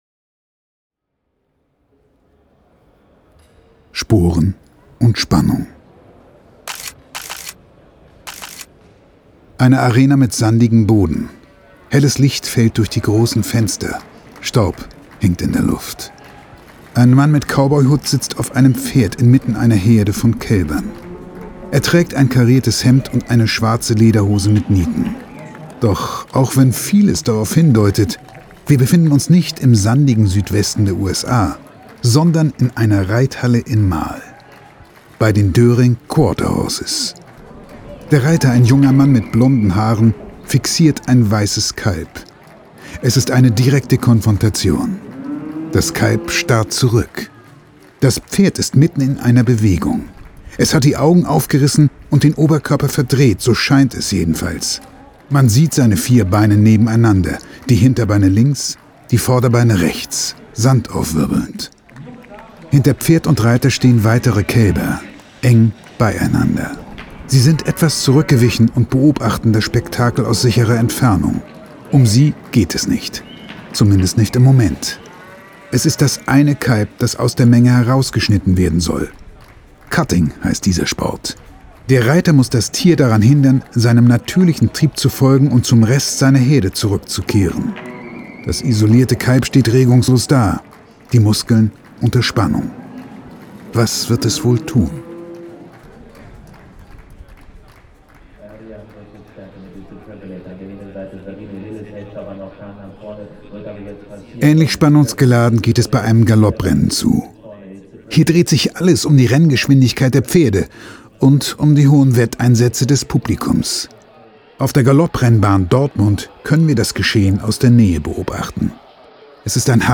Unser Hörbuch genießen Sie am besten mit Kopfhörern .
pferde2020_hoerbuch_taste_6__sporenundspannung__master.mp3